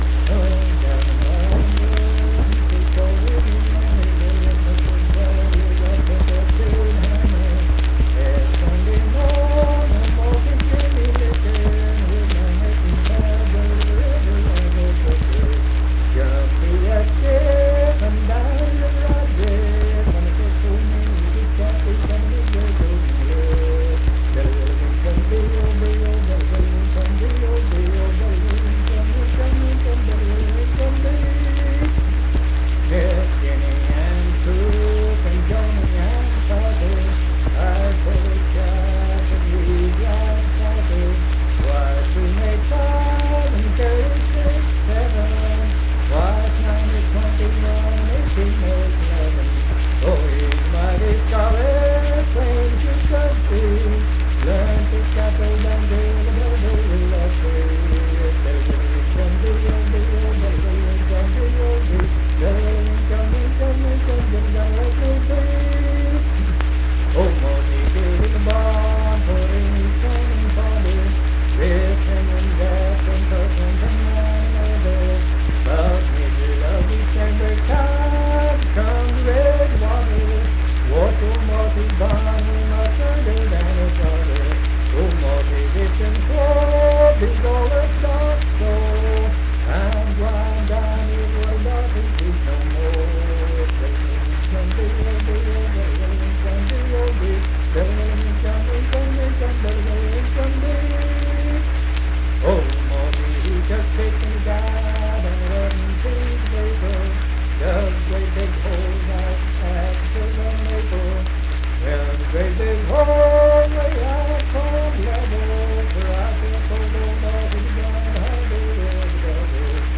Unaccompanied vocal music
Verse-refrain 5(8)&R(8). Performed in Kanawha Head, Upshur County, WV.
Children's Songs
Voice (sung)